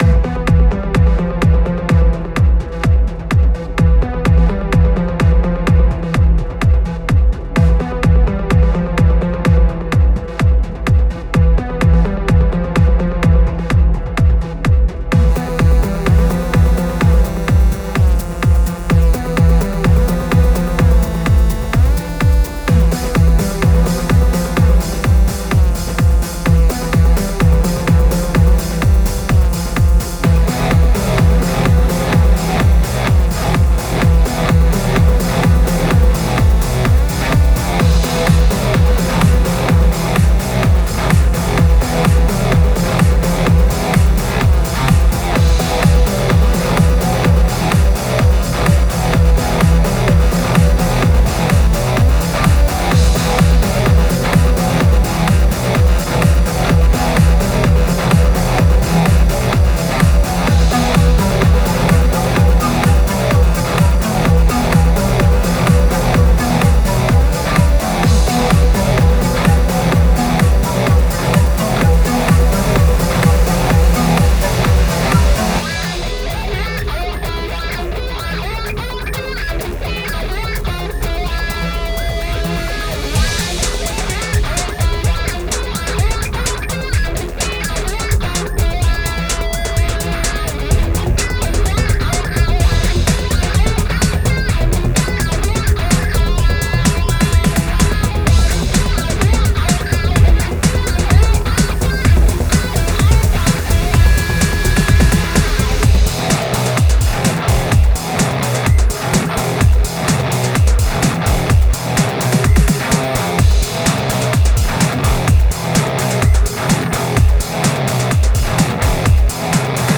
It's a demo track, but I thought it was worth sharing.
I didn't get much info on the track, but I do know from their previous music career that they most likely did the whole track, guitar and everything.